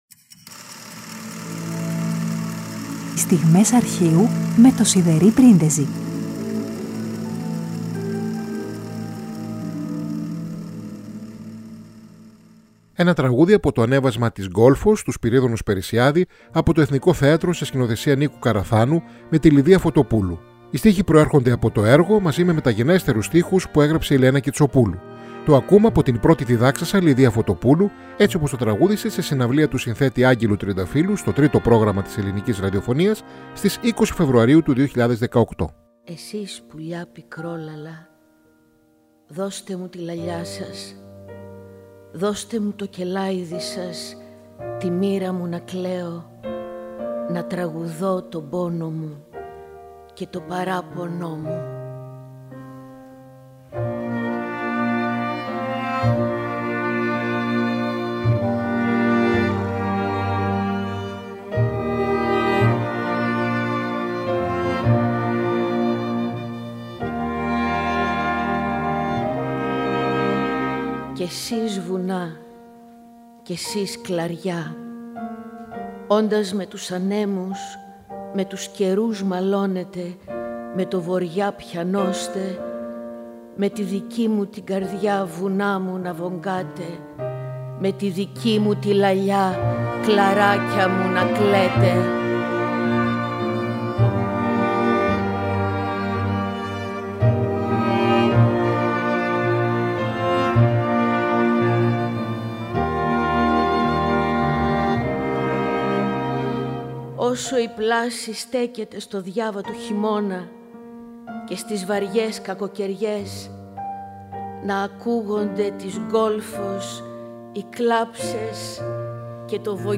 τραγούδια ηχογραφημένα στο ραδιόφωνο